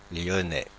Ääntäminen
Ääntäminen Paris Haettu sana löytyi näillä lähdekielillä: ranska Käännöksiä ei löytynyt valitulle kohdekielelle.